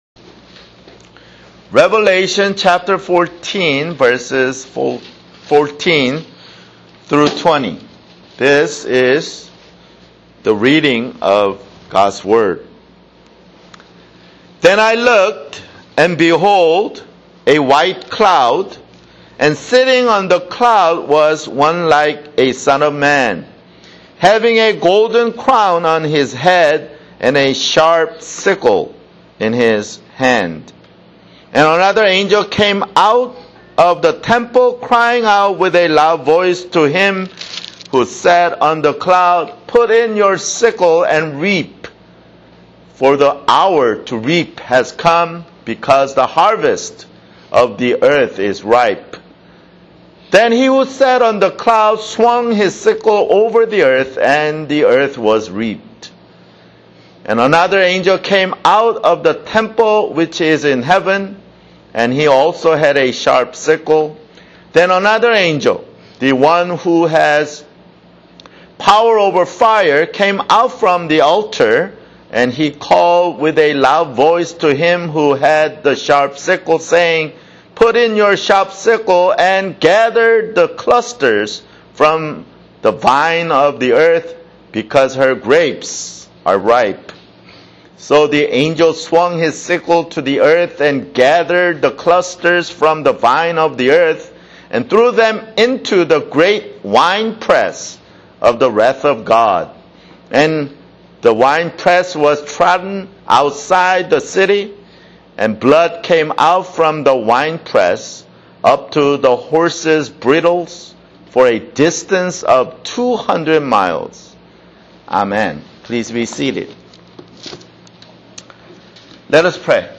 [Sermon] Revelation (52)